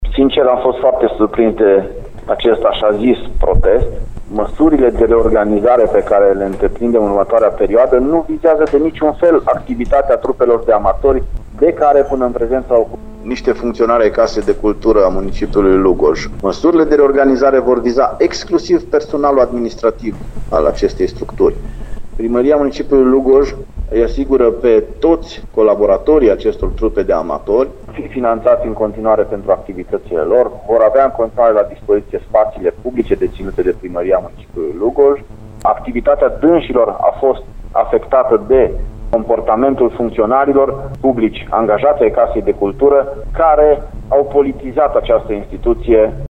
Primarul Claudiu Buciu neagă, însă, desființarea teatrului și a corulului. El precizează că transformarea Casei de Cultură în Direcție de cultură, se face doar pentru disponibilizarea funcționarilor, care, în trecut, au plătit din bugetul instituției mai multe baluri organizate de un anumit partid politic, fapt constatat și de Curtea de Conturi.